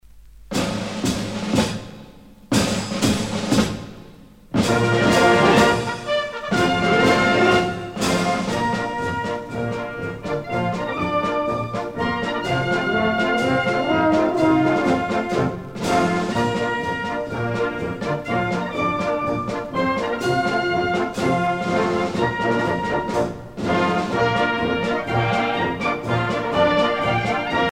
gestuel : à marcher
Pièce musicale éditée